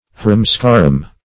Harum-scarum \Har"um-scar"um\ (h[^a]r"[u^]m*sk[^a]r"[u^]m), a.